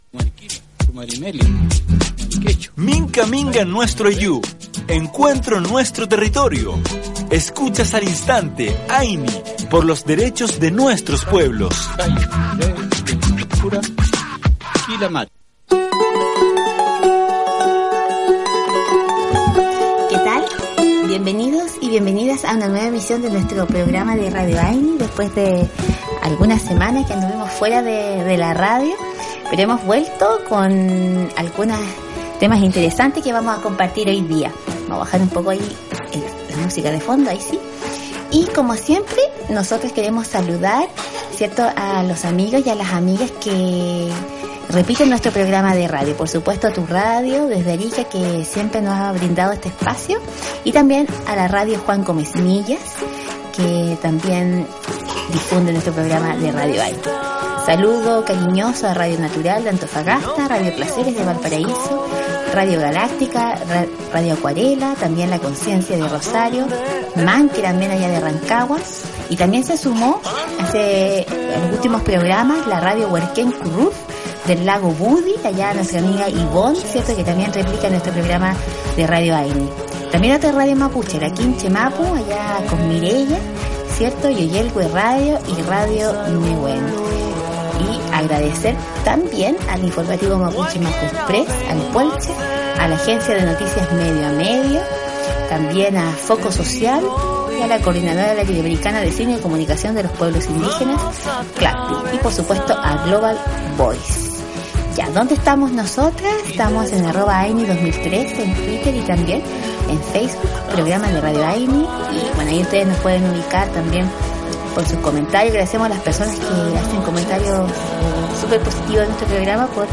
Ayni es un espacio radial para la promoción, educación y defensa de los derechos humanos,actualidad de los pueblos originarios, medio ambiente y movimientos sociales.